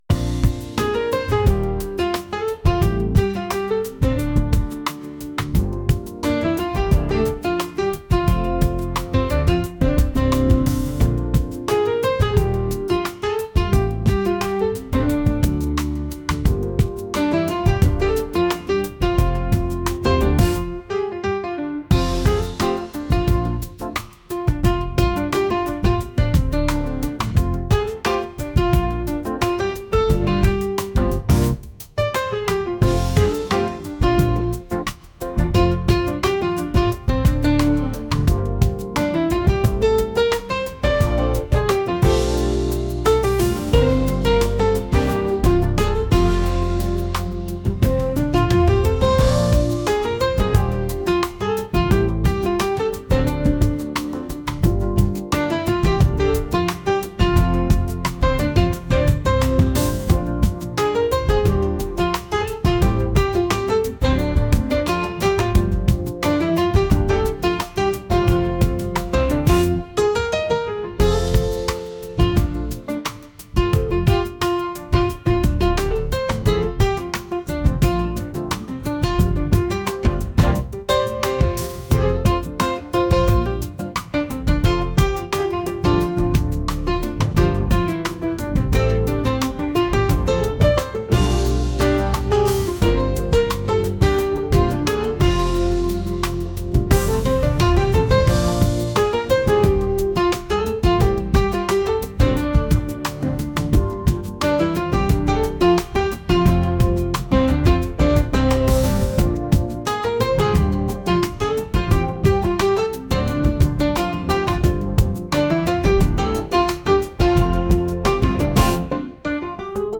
jazz | soul & rnb | lounge